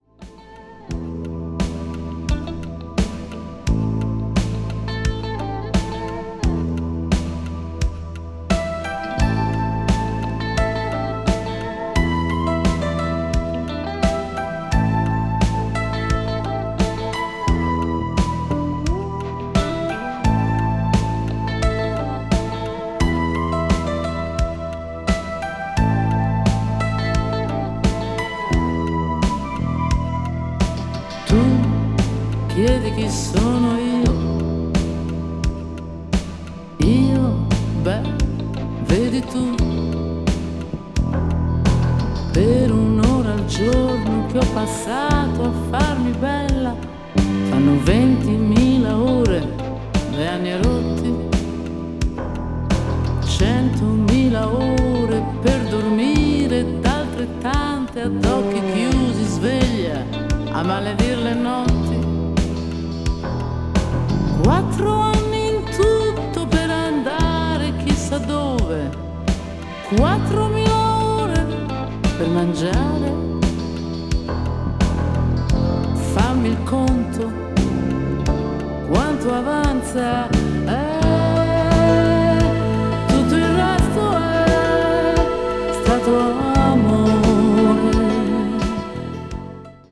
a.o.r.   adult pop   canciones   italian pop   mellow groove